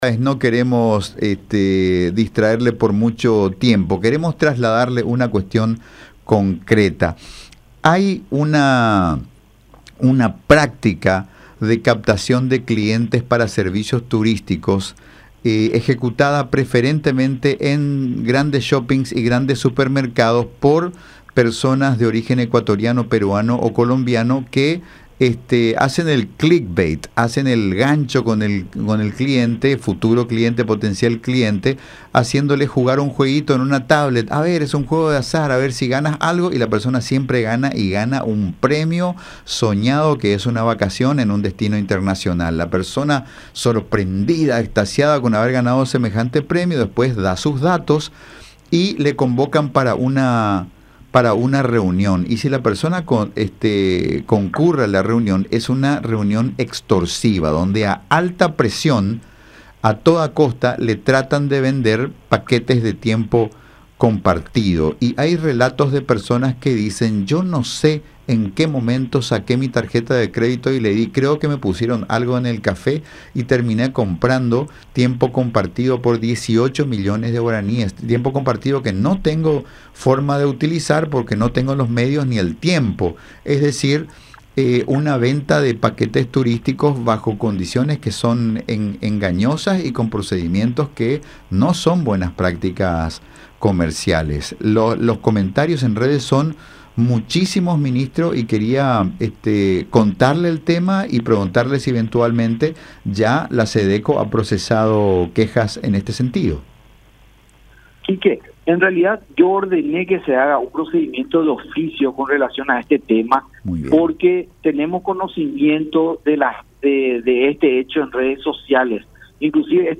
“Yo ordené que se haga una investigación de oficio con las agencias de turismo que están estafando. Queremos evaluar todo esto y remitir los antecedentes a Fiscalía”, expuso Juan Marcelo Estigarribia, ministro de SEDECO, también en contacto con La Unión.
23-JUAN-MARCELO-ESTIGARRIBIA.mp3